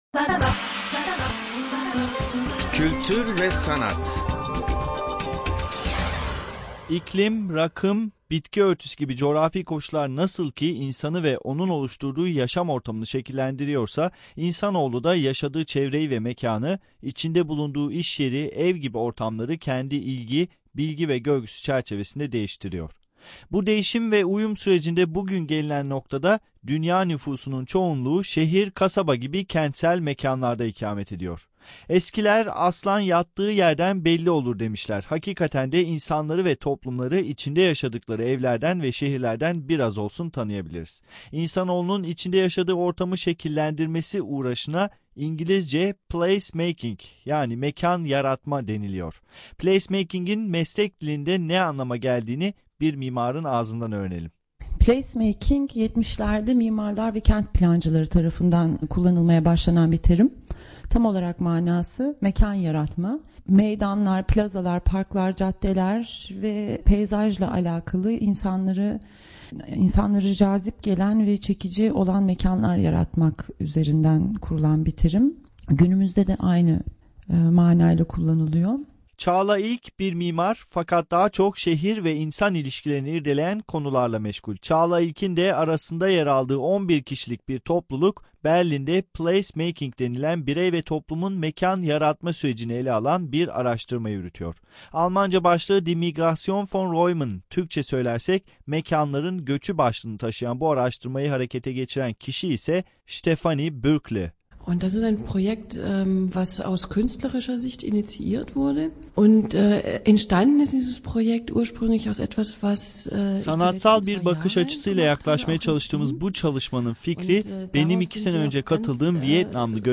Radiobeitrag 15. Juli 2008 auf multikulti